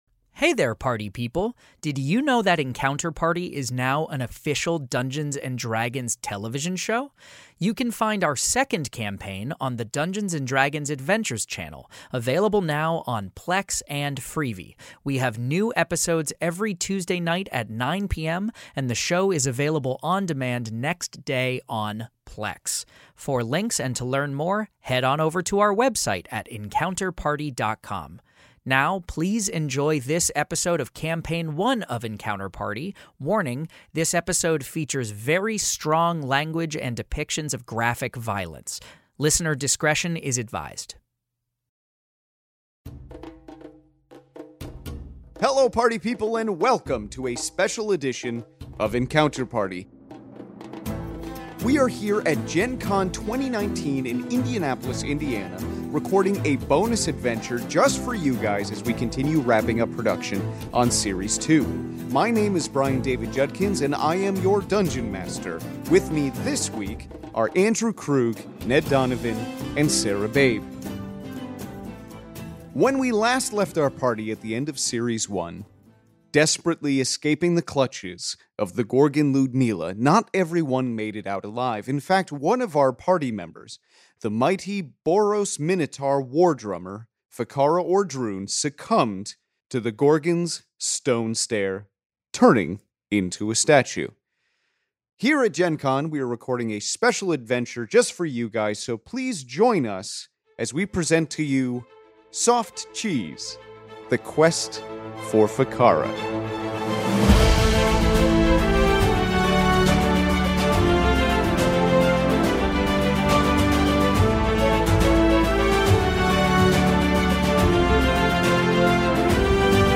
Fantasy Mystery Audio Adventure